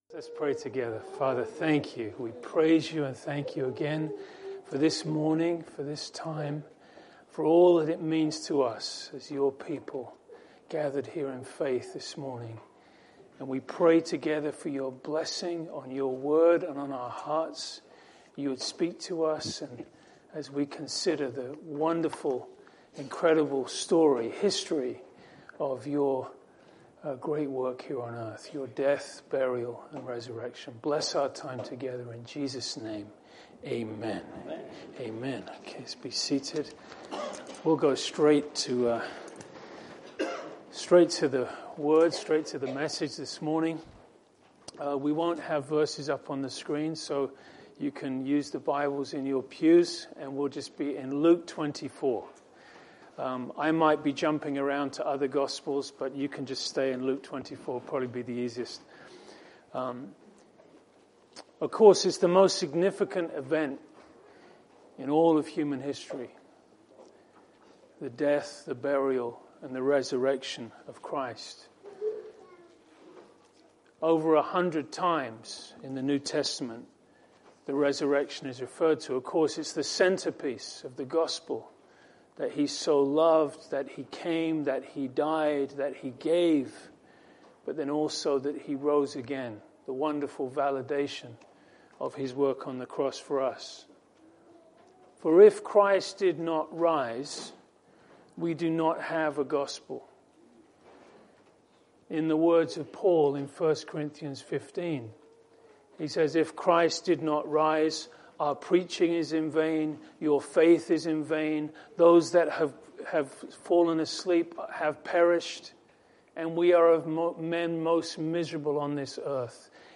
Fir this easter Sunday message we journey through this chapter and consider the resurrection appearances and restored faith.